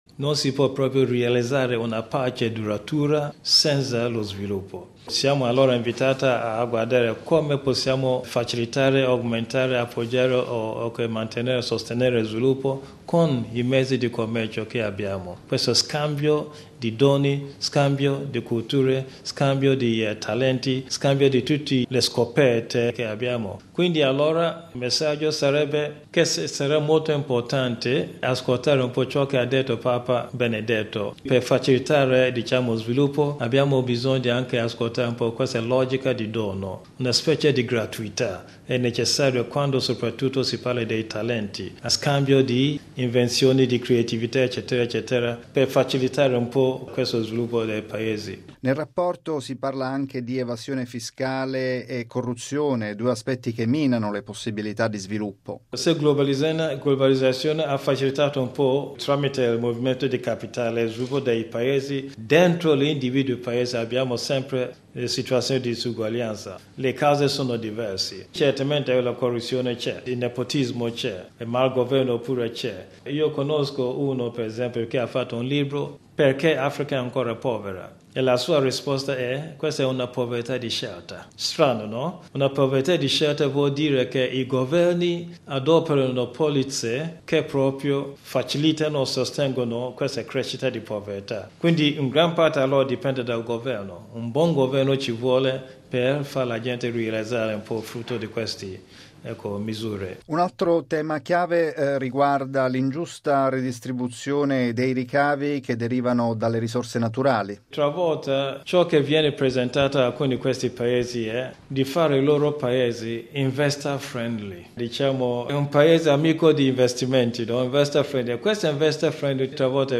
Alla presentazione del rapporto a Roma era presente anche il cardinale Peter Turkson, presidente del Pontificio Consiglio della Giustizia e della Pace, che ha parlato del legame tra sviluppo e pace.